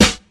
Alchemist Snare 1.wav